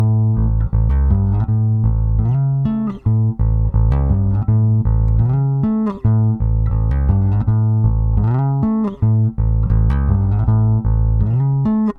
描述：不知道这可以归入哪一类它有点像Cypress Hill的风格。它在80bpm的节拍中略微 "落后 "地演奏。基于A9A11的音符。
标签： 80 bpm Rock Loops Bass Loops 2.20 MB wav Key : A
声道立体声